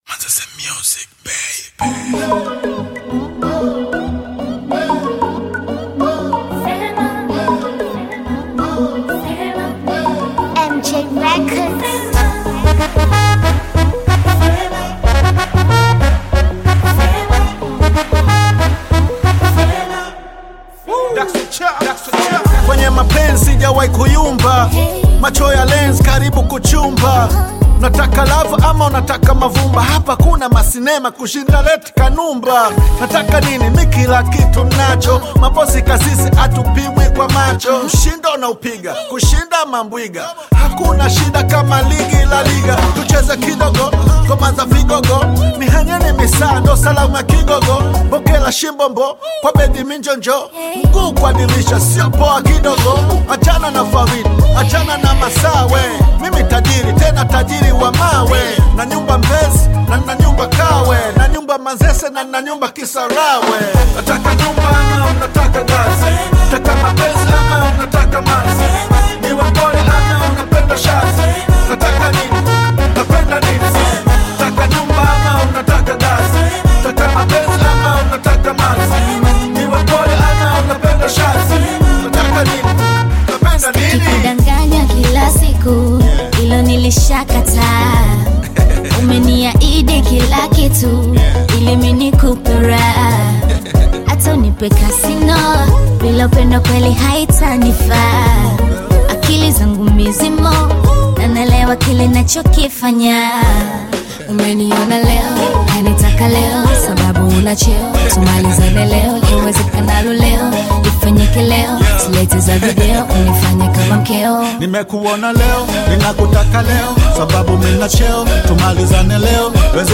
Tanzanian Bongo Flava/Hip-Hop single
smooth rap delivery
captivating vocals over rhythmic melodies